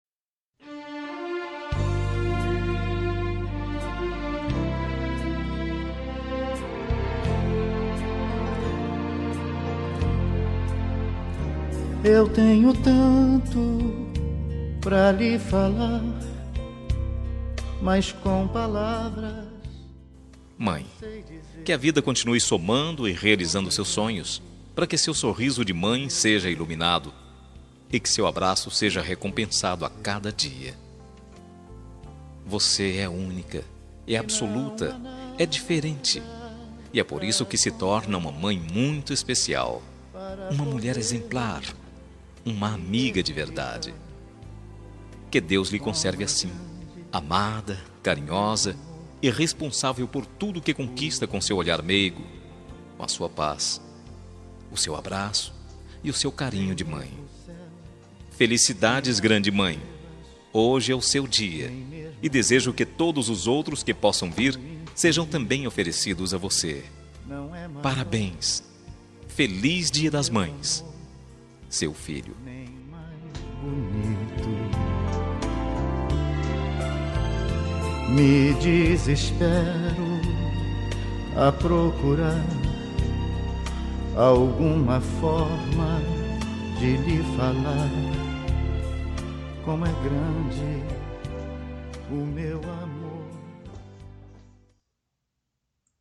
Dia das Mães – Para minha Mãe – Voz Masculina – Cód: 6517